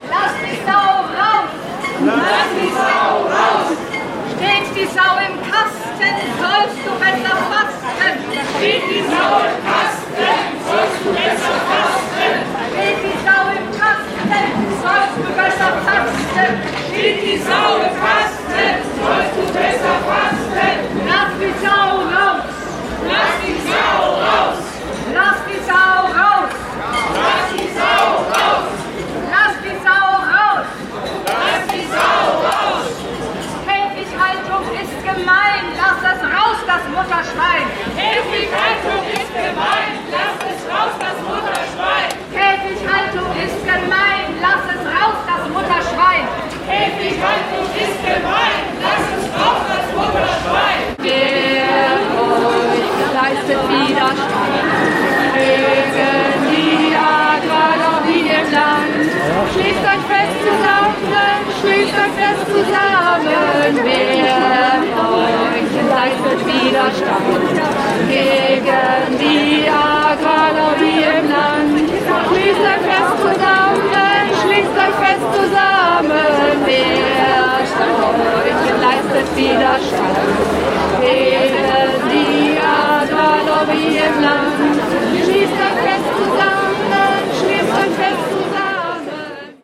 Demonstration: 10. „Wir haben es satt!“-Demonstration (Audio 14/20)
Einige Protest-Slogans (zusammengeschnitten) (Audio 14/20) [MP3]